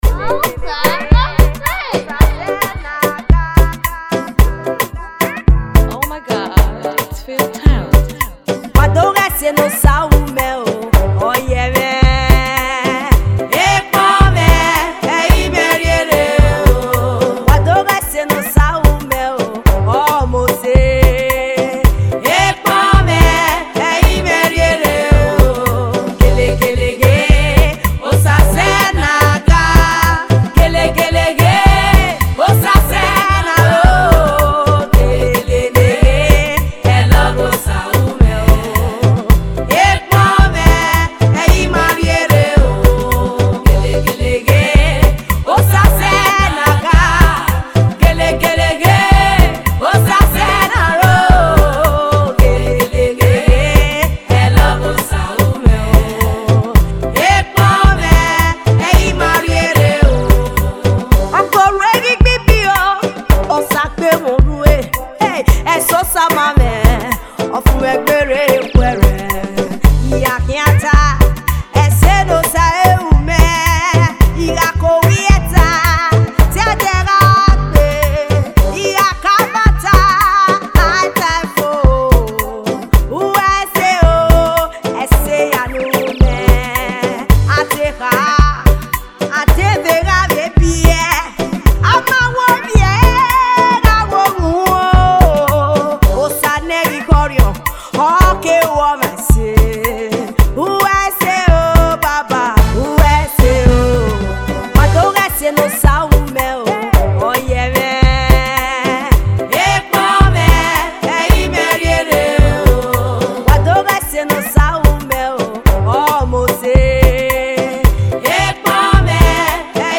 a new song of joy
Gospel song